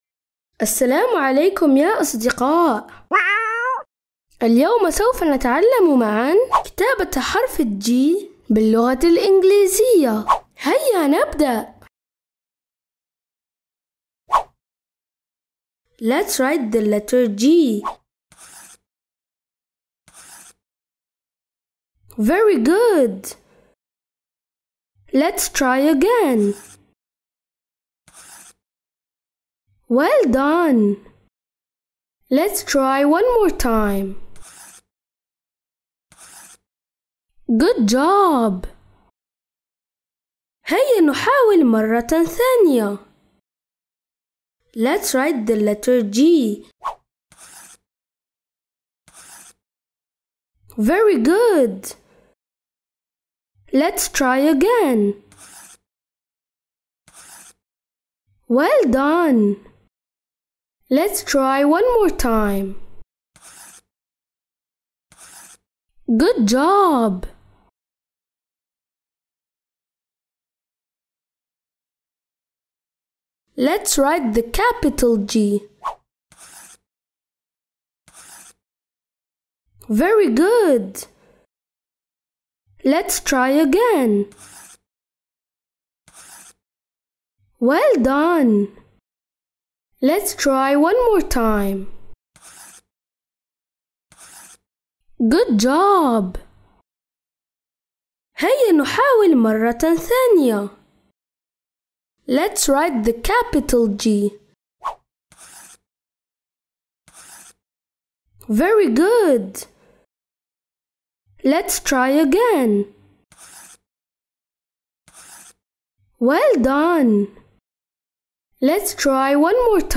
برنامج تعليم اللغة الانجليزية
برنامج تعليم اللغة الانجليزية تستمعون إليه عبر إذاعة صغارنا كل احد الساعة 1:00 مساء